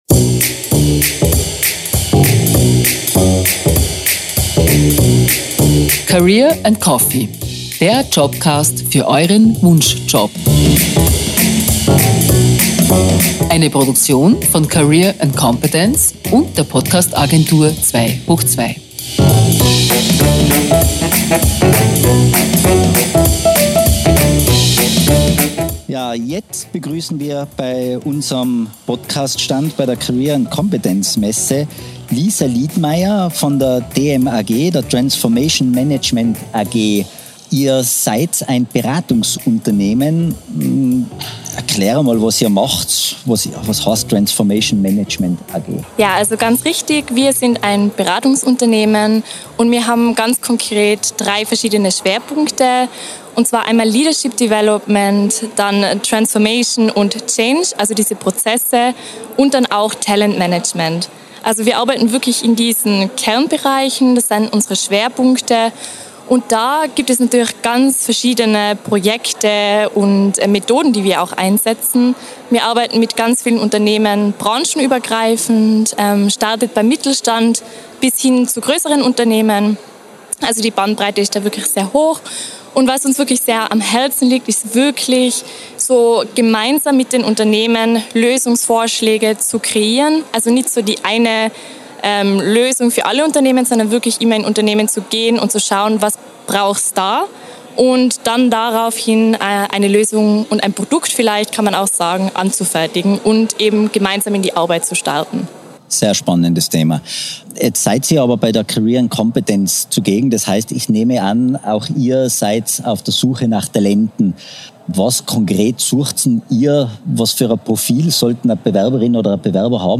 Livemitschnitt von der Career & Competence-Messe mit
Masterlounge in Innsbruck am 4. Mai 2022.